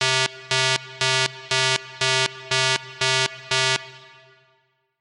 Download Classic Siren Alarm sound effect for free.
Classic Siren Alarm